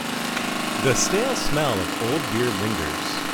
下载。如果你听这个音频文件，你会注意到它有很多背景噪音。
jackhammer.wav